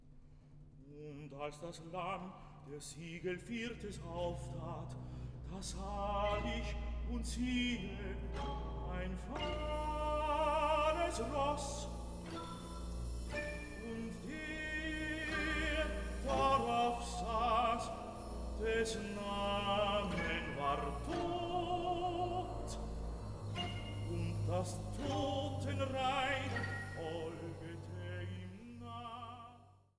Oratorium